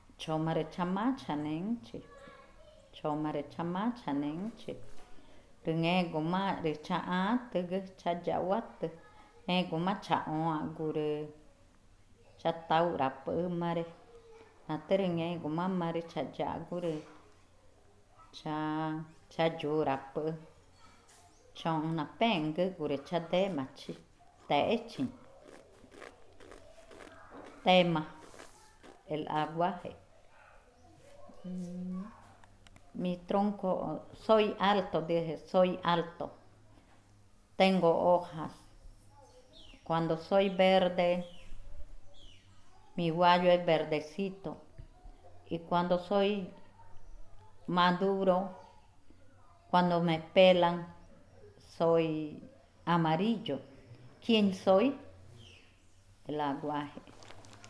Cushillococha